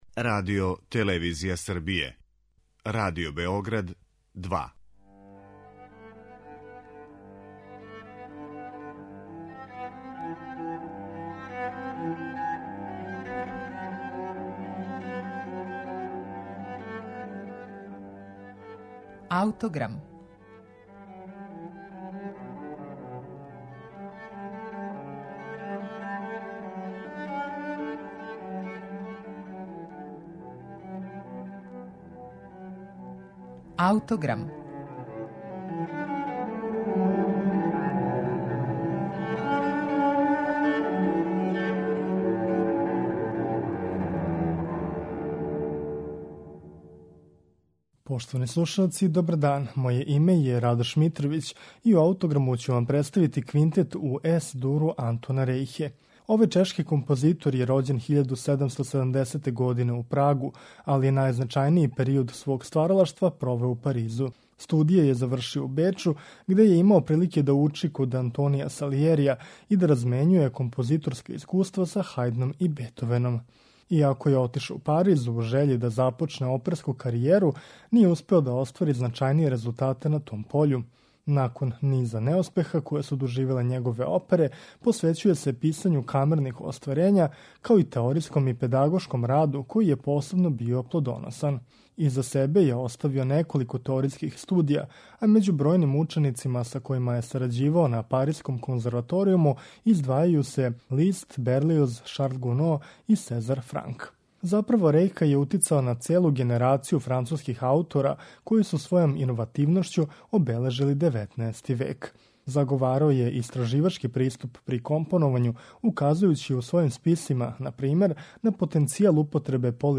Антон Рејхa: Дувачки квинтет
У емисији Аутограм, слушаћемо Рејхин најпознатији квинтет, у Ес-дуру, из 1818. године, у извођењу Израелског квинтета.